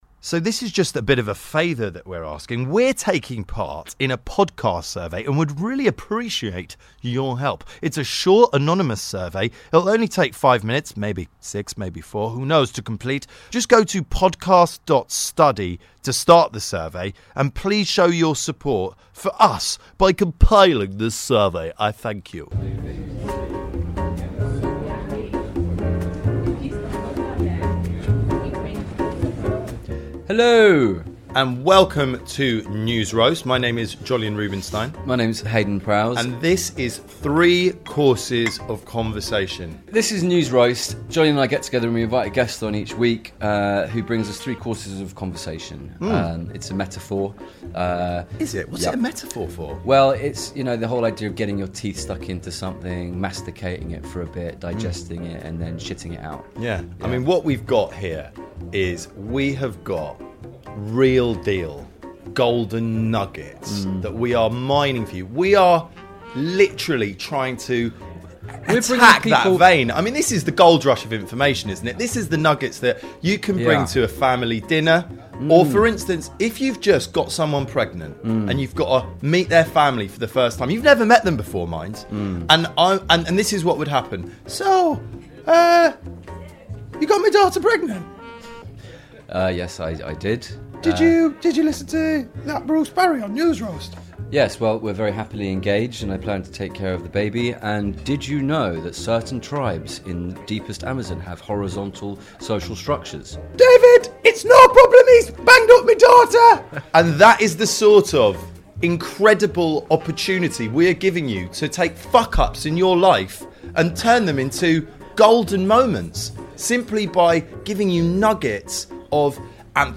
Bruce Parry - award-winning documentarian, author & explorer joins the boys to discuss some of his incredible adventures and how it relates to his outlook on life. "Equality", "Healing" and "Connection" are on today's menu.